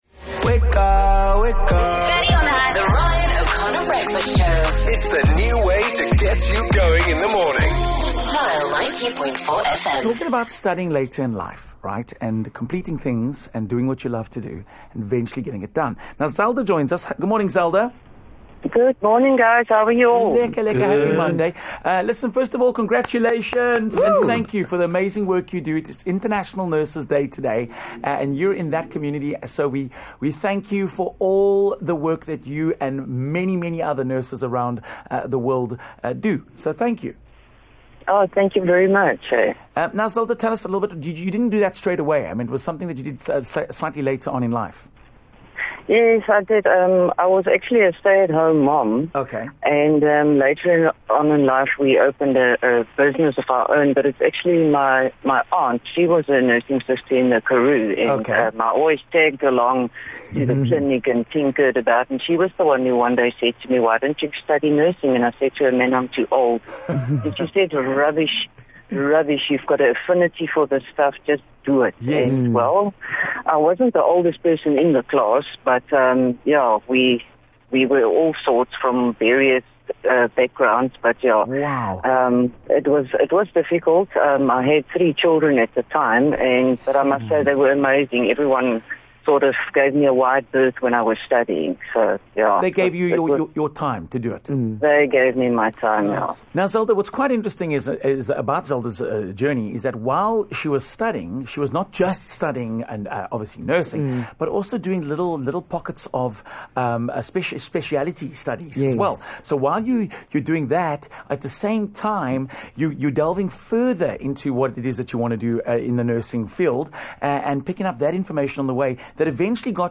Today is International Nurses Day with the theme being "Our Nurses. Our Future". One of our callers who is a nurse phoned in to tell us about her journey and showed just how much of a calling the profession is.